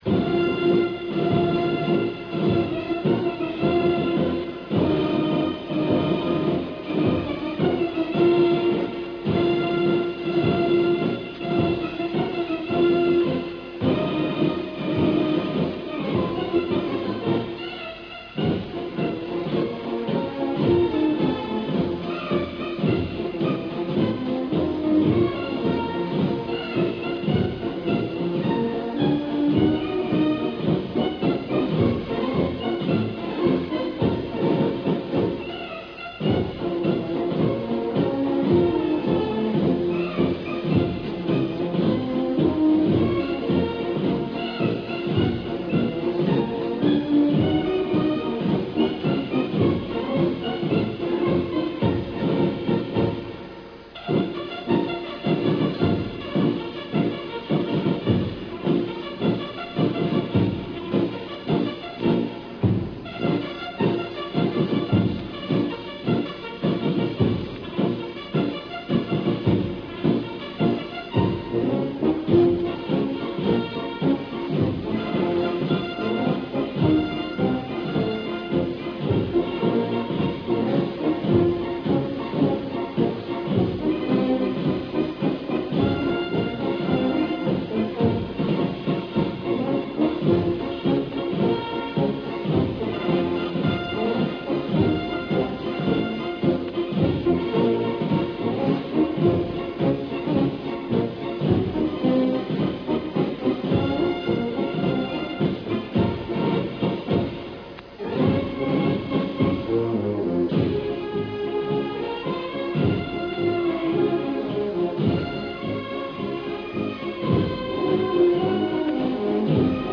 MILITARY SONGS